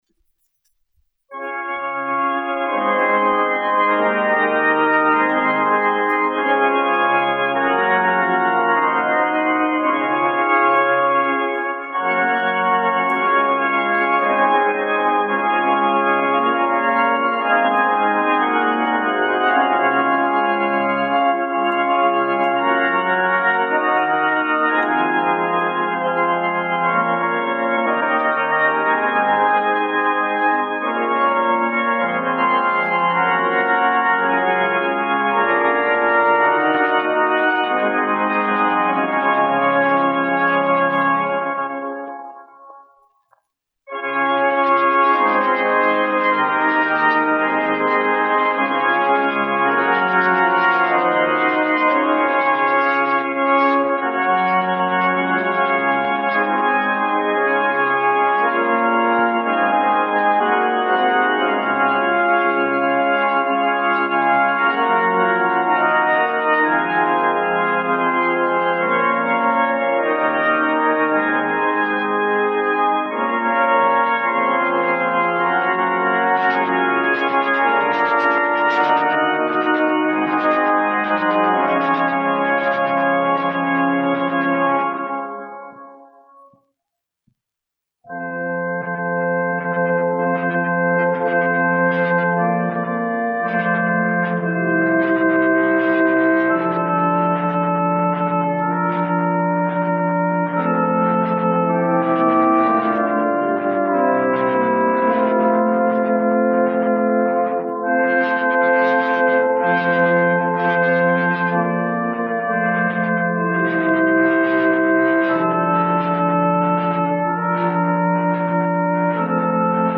1 skpl. : analogs, 78 apgr/min, mono ; 25 cm
Ziemassvētku mūzika
Ērģeļu mūzika
Skaņuplate